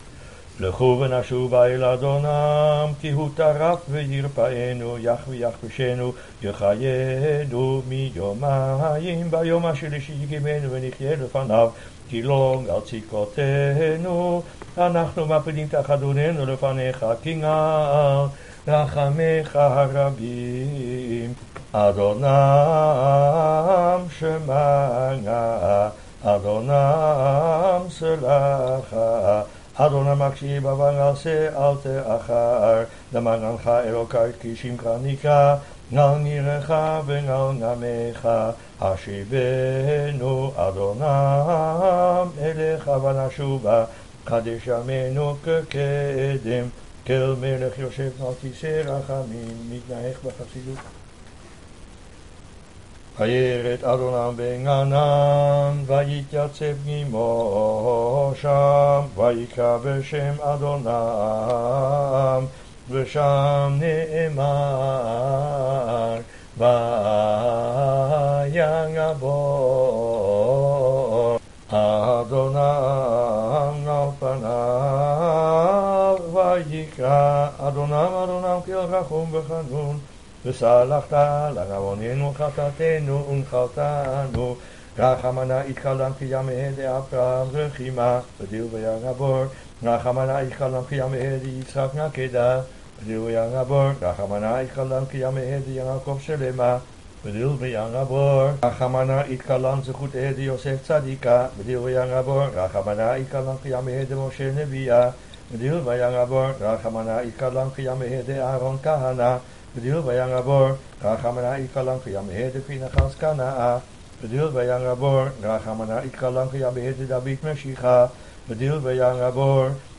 Evening_selichot.mp3